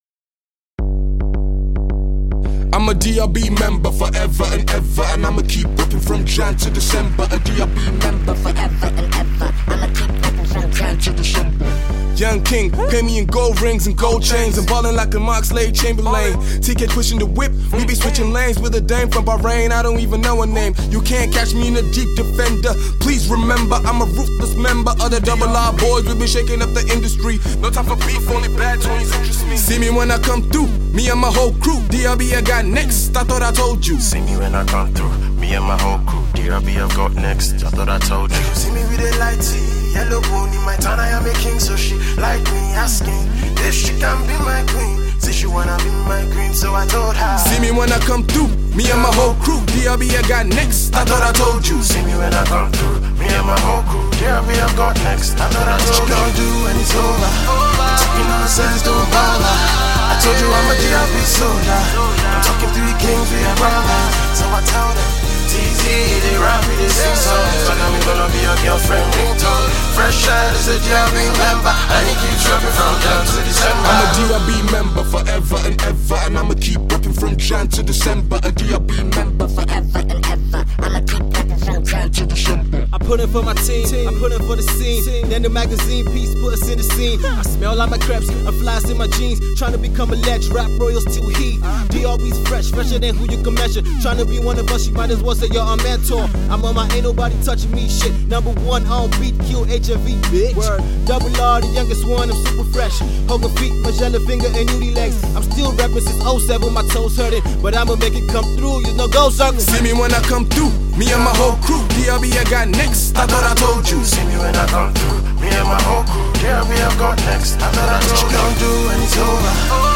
It’s not in its finished form yet but it’s still a banger!